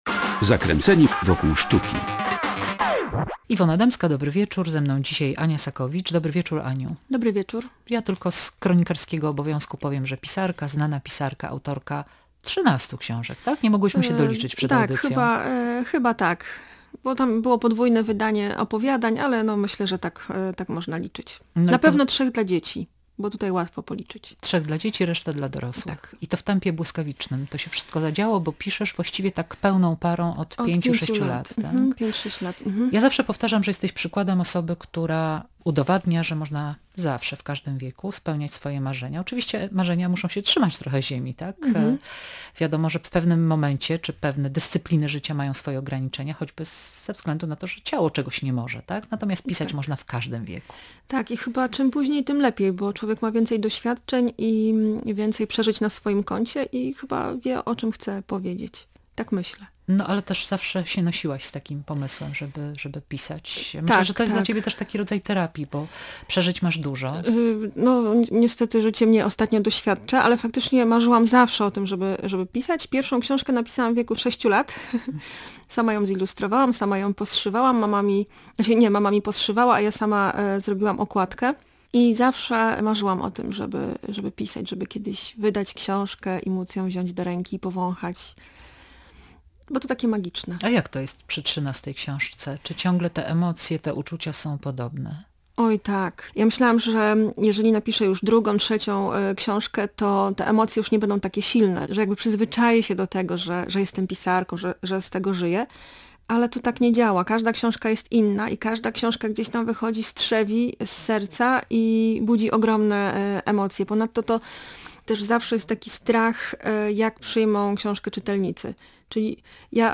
Mieszka z nami Alzheimer – rozmowa z pisarką